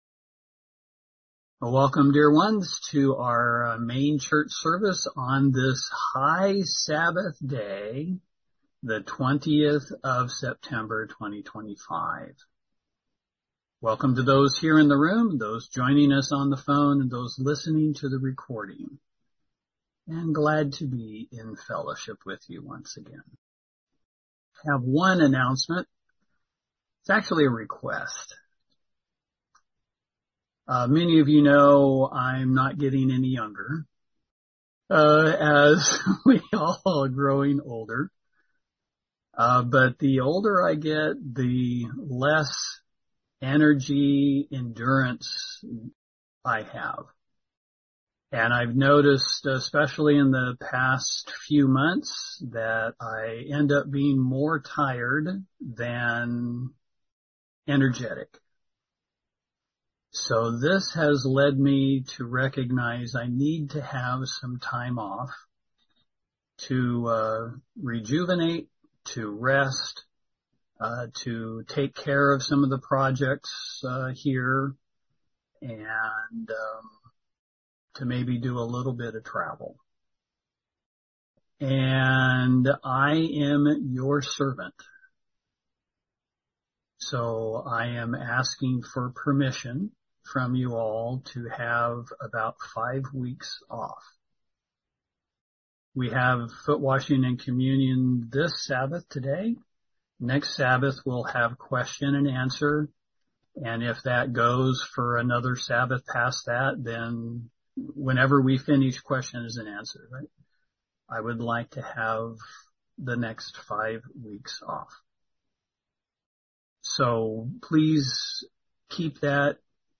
LTBL-sermon-(9-20-25).mp3